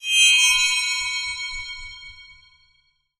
magic_shinny_high_tone_02.wav